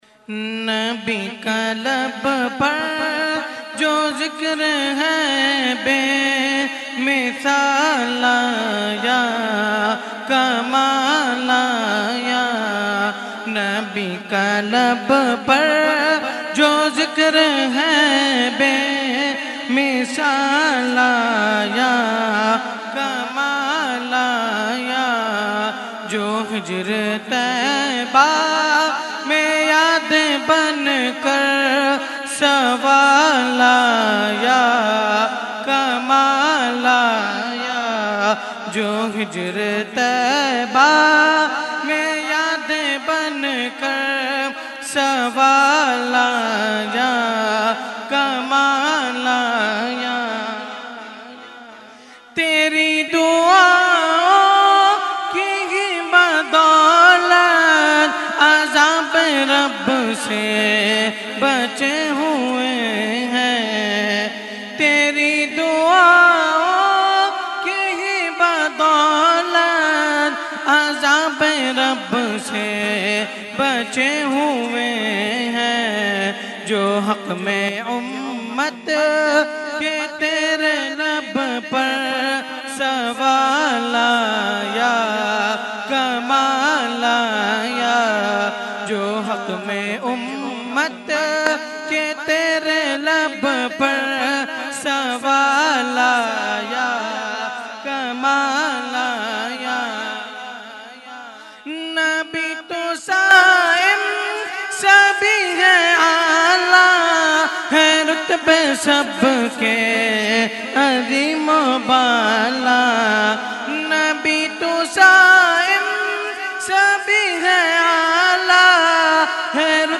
held on 21,22,23 December 2021 at Dargah Alia Ashrafia Ashrafabad Firdous Colony Gulbahar Karachi.
Category : Naat | Language : UrduEvent : Urs Qutbe Rabbani 2021-2